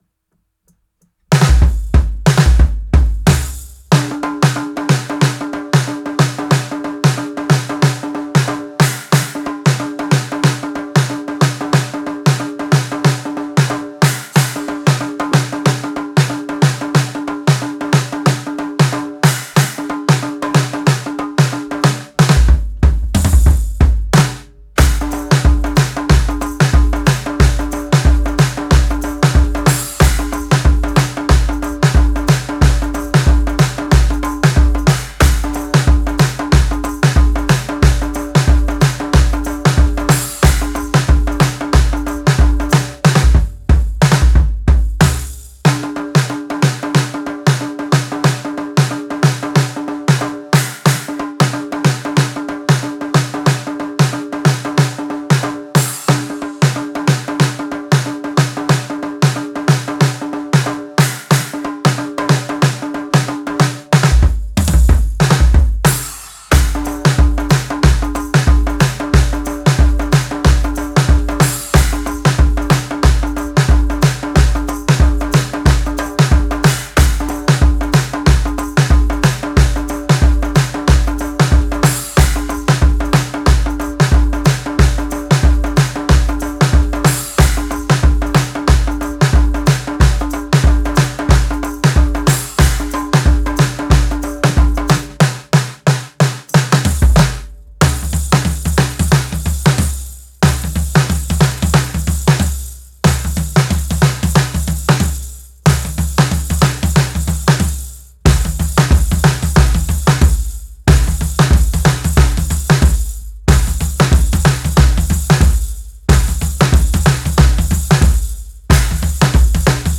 Wonky Pop
Genre:Wonky Pop, Bhangra
Tempo:92 BPM (4/4)
Kit:Audition Japanese vintage 16"
Mics:14 channels